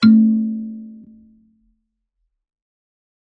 kalimba2_wood-A2-ff.wav